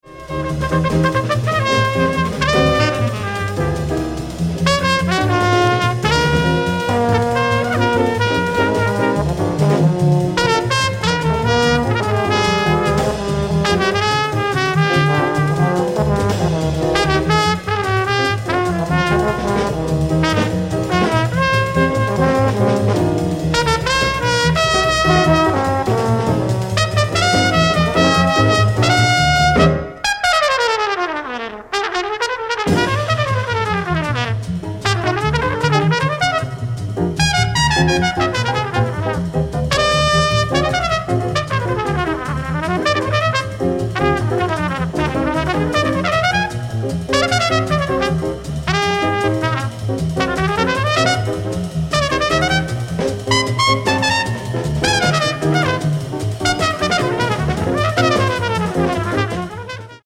hard-bop
trumpet
trombone
tenor sax
piano
bass
drums